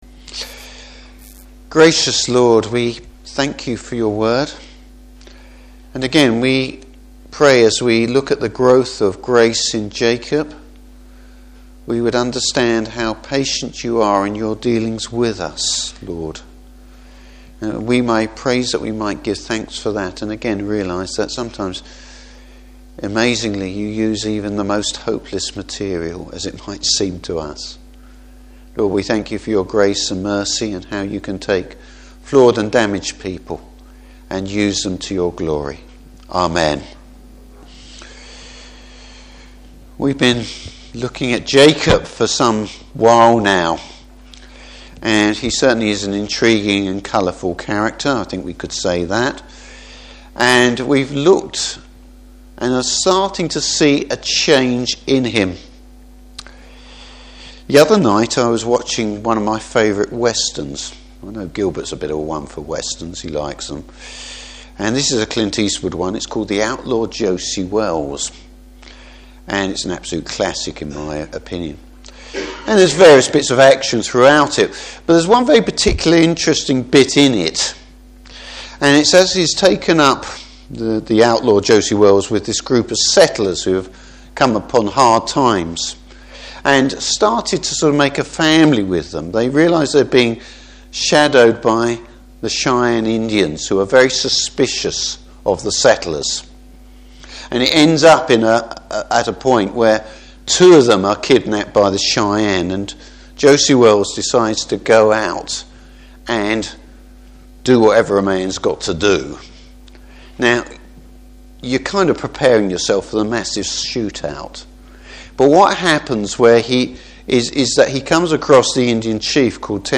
Service Type: Evening Service How the Lord has been one step ahead of Jacob in everything!